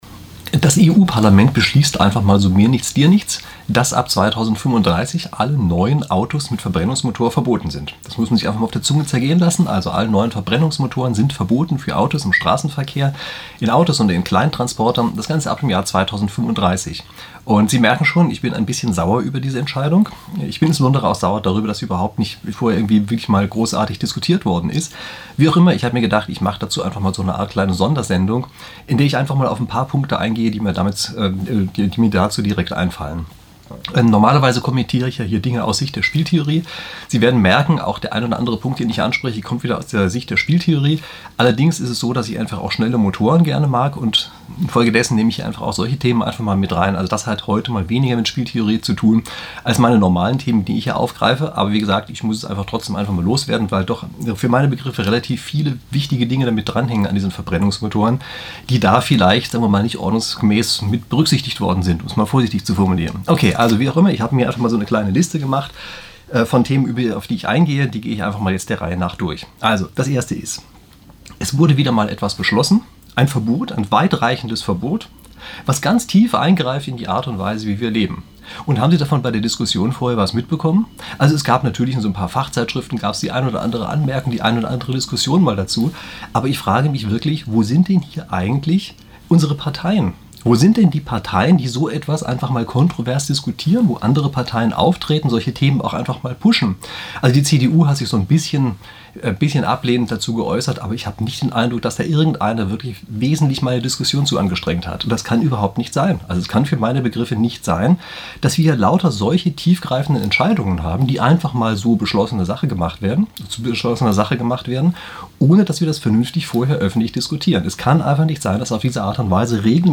Neun Gründe, wieso das Verbrenner-Verbot Unfug ist. Das EU-Parlament hat es trotzdem beschlossen. Ein Rant mit spieltheoretischen Einwürfen.